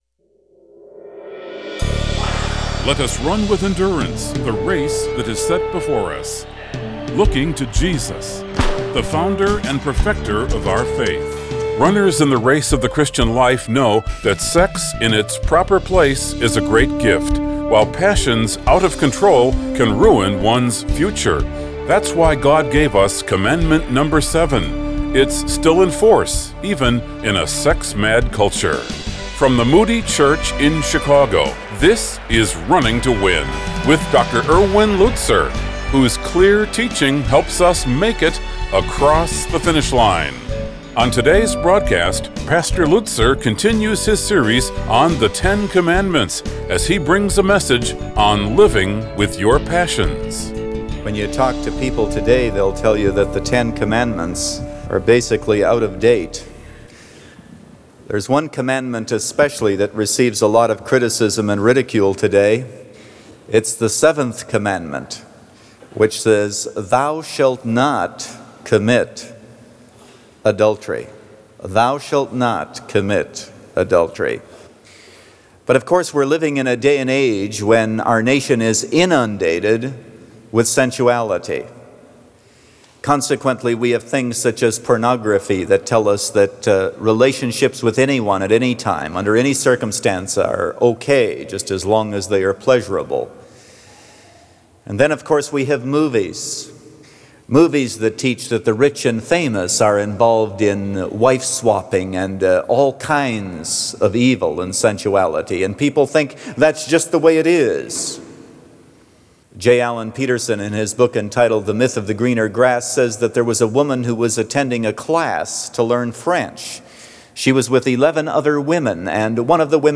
Living With Your Passions – Part 1 of 3 | Radio Programs | Running to Win - 15 Minutes | Moody Church Media